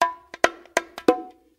bongo1.ogg